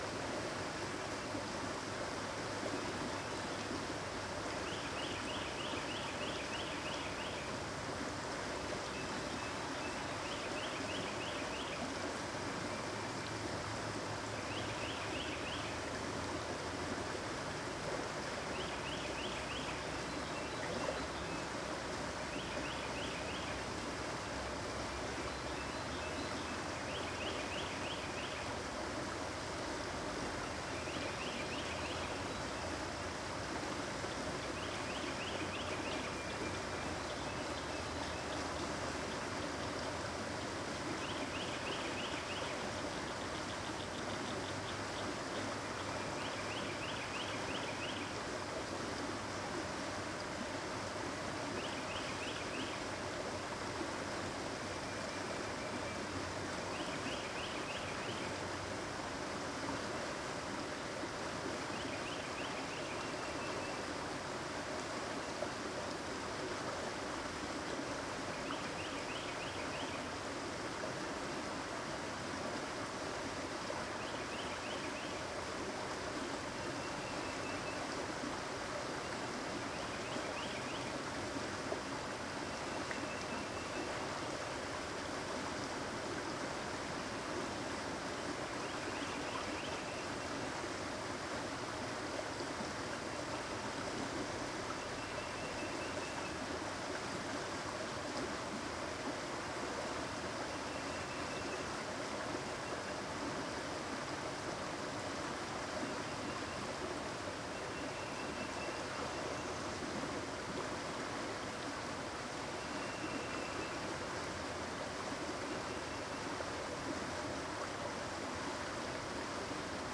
Afternoon Suburban Park Babbling Brook Birds ST450 02_ambiX.wav